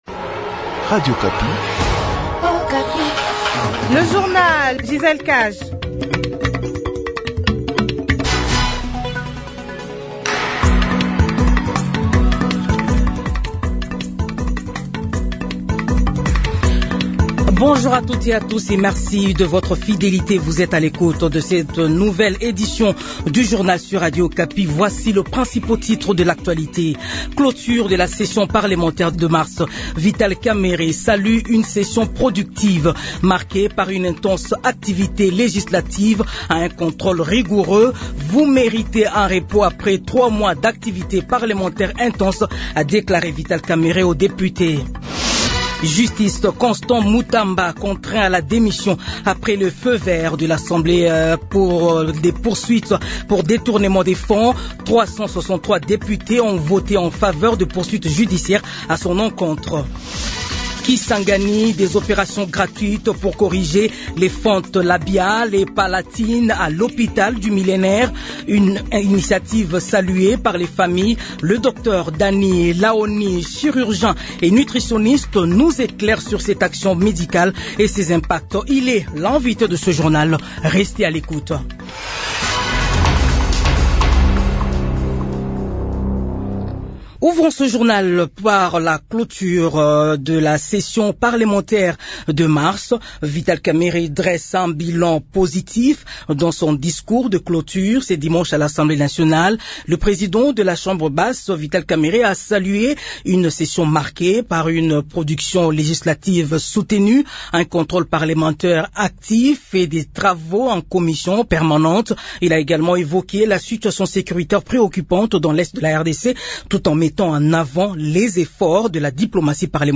Journal 15h